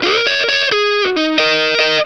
BLUESY3 EF90.wav